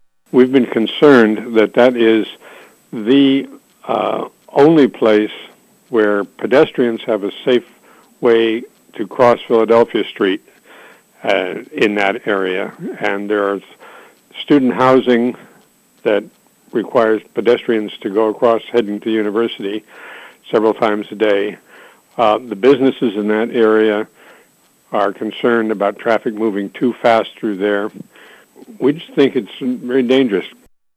Indiana Borough Council has been against the idea of removing the traffic signal.  Council President Peter Broad says that they are considering the safety of not just the drivers, but pedestrians as well.